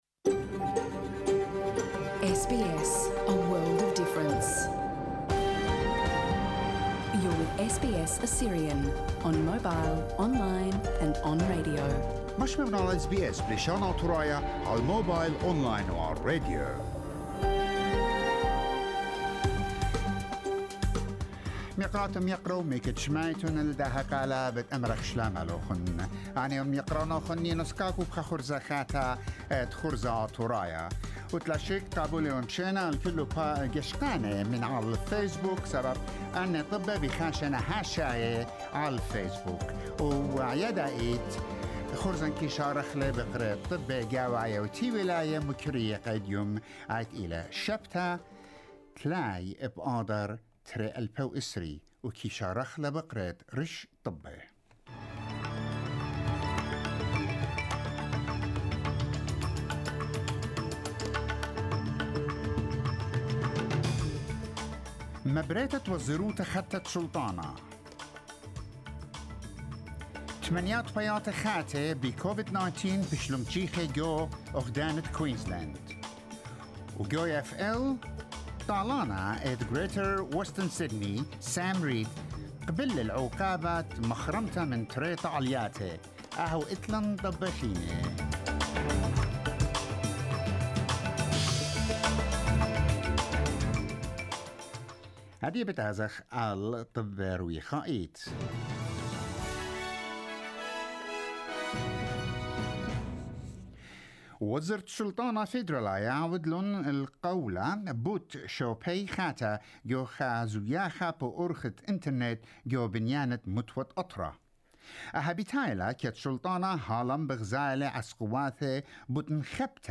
NEWS TUESDAY 30 MARCH 2021